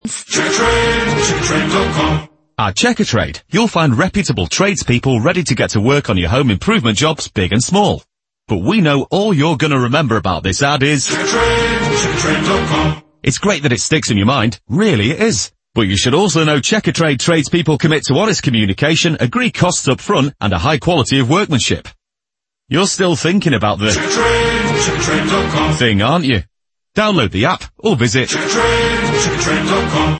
And our Ad of the Month for January, sees Checkatrade humorously play the victim of its catchy tagline’s success, much to the frustration of the narrator.
Throughout the radio ad, created by St Luke’s, the narrator has a terrible time trying to land messages about the reputable tradespeople available for hire on the site. Despite his best efforts, he just can’t help but be interrupted by the all-encompassing earworm.